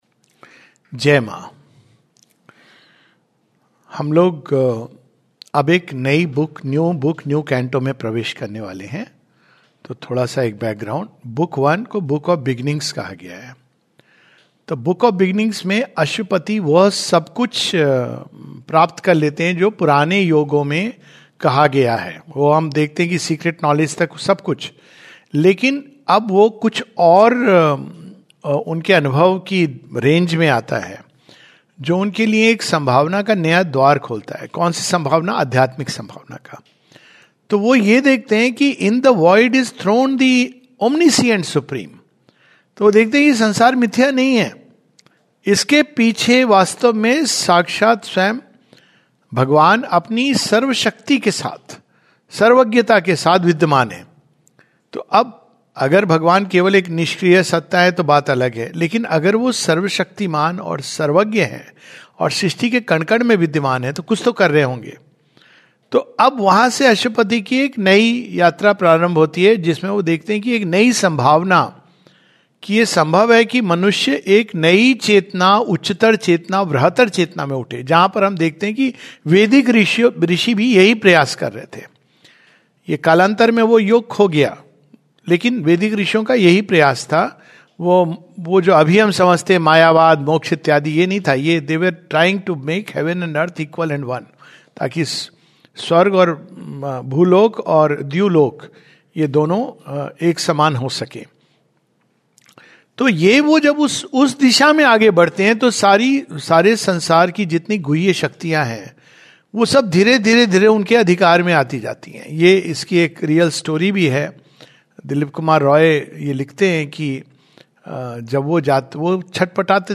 The passages from Book Two Canto 1 (pages 95-96) were read.